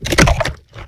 flesh1.ogg